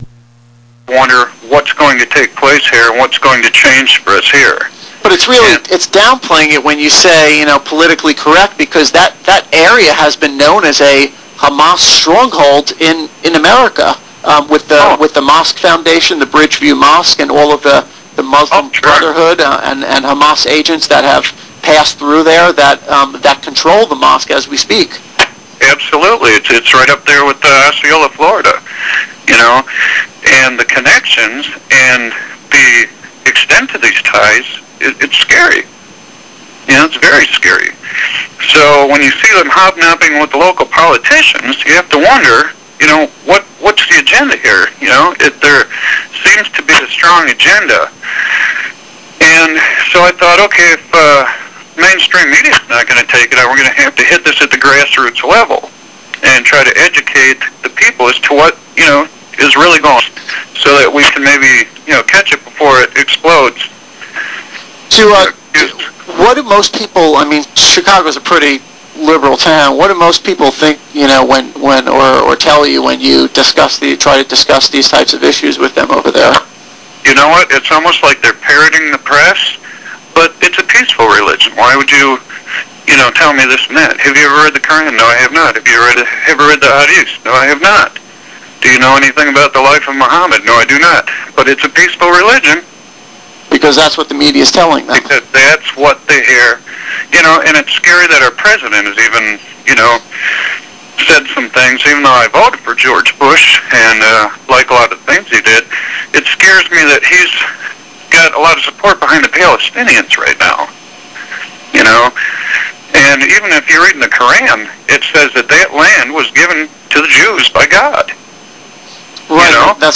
Radio Interview on Kol Halev